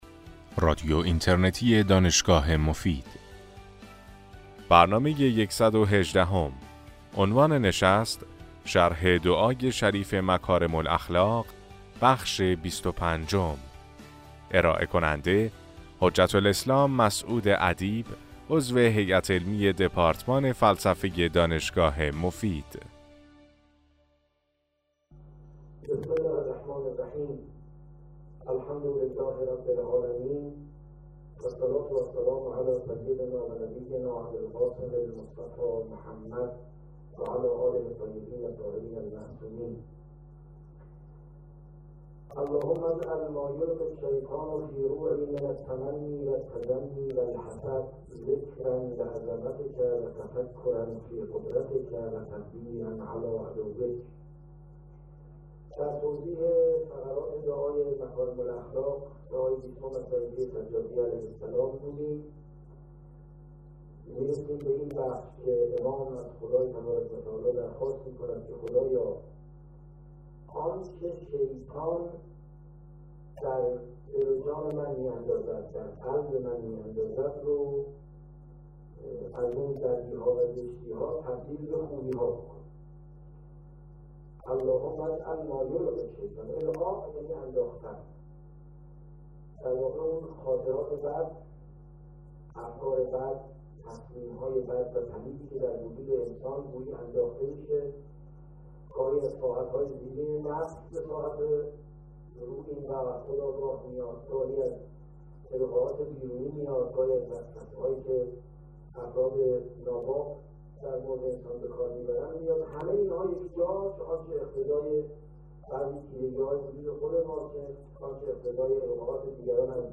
در این سلسله سخنرانی که در ماه رمضان سال 1395 ایراد شده است